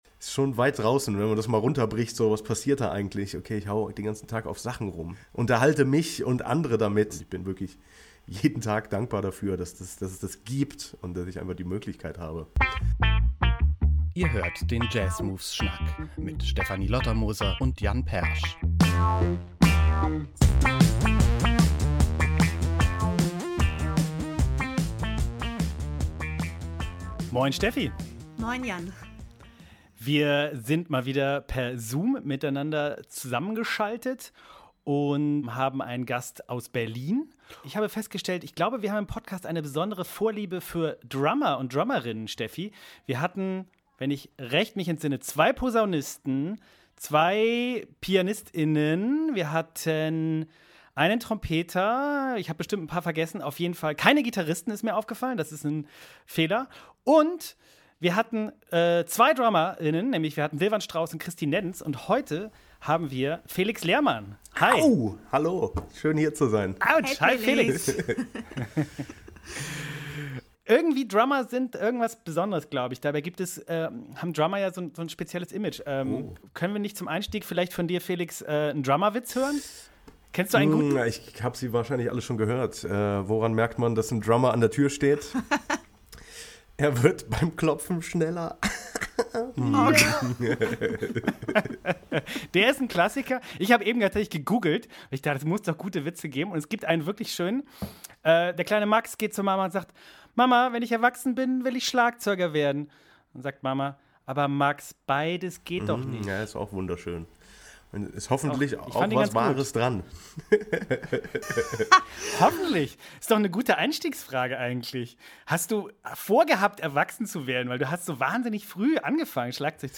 Dass er in eigenen Projekten wie "Marriage Material" aber auch komplexe Jazz-Songs aufnimmt, warum er trotzdem gerne Pop spielt und an welche Plattenaufnahmen er sich weniger gern erinnert, erzählt der Berliner uns gutgelaunt im Schnack.